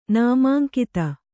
♪ nāmāŋkita